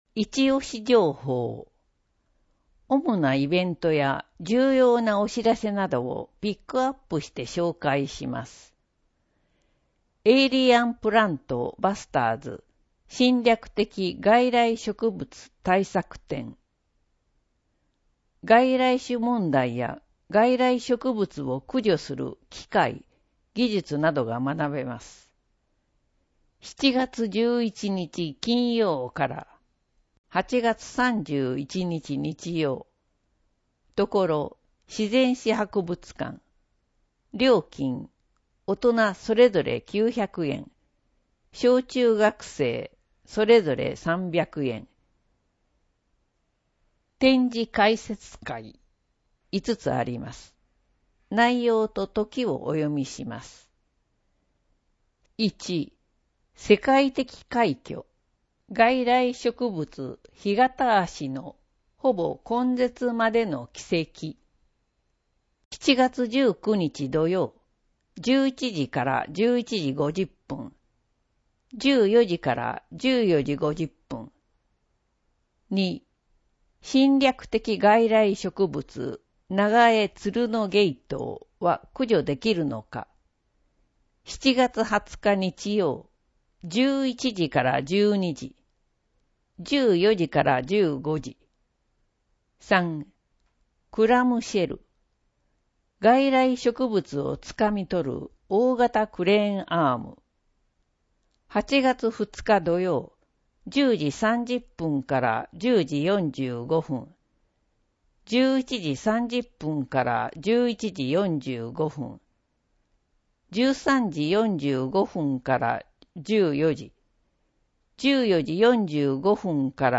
• 「広報とよはし」から一部の記事を音声でご案内しています。視覚障害者向けに一部読み替えています。